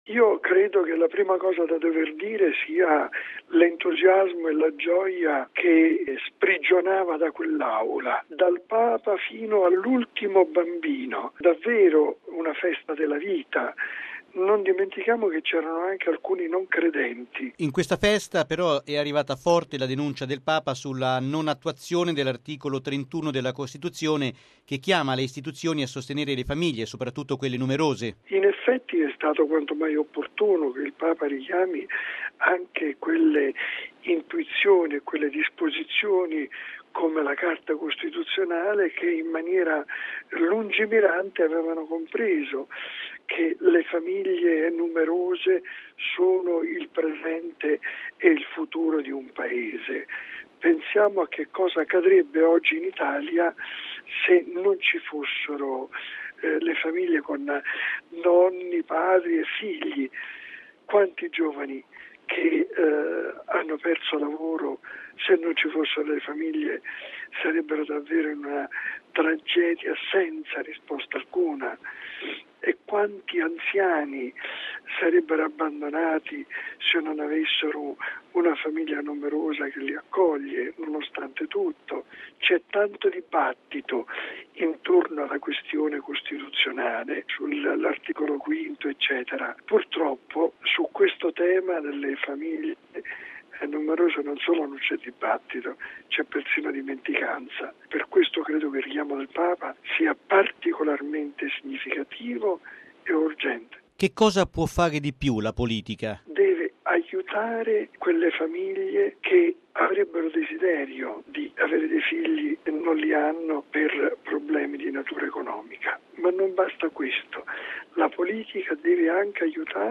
Un evento festoso cui ha partecipato anche mons. Vincenzo Paglia, presidente del Pontificio Consiglio per la Famiglia.